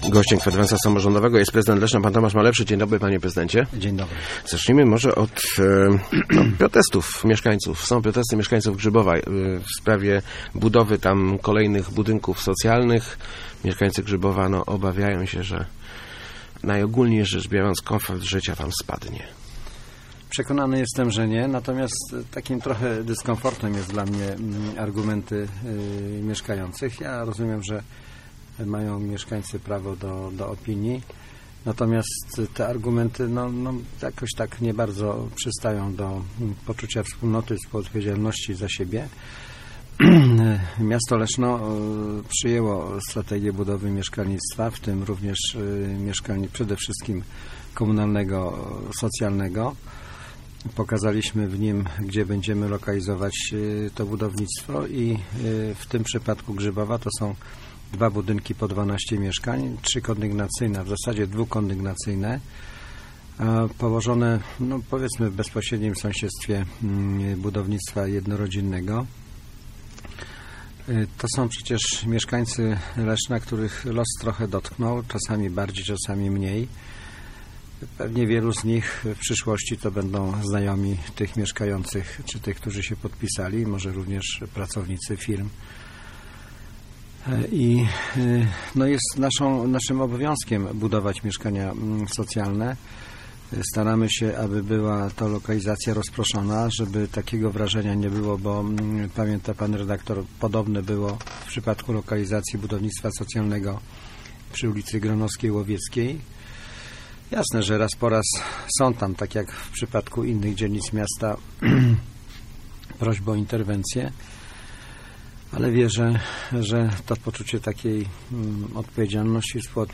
Gościem Kwadransa był prezydent Tomasz Malepszy.